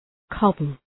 Προφορά
{‘kɒbəl}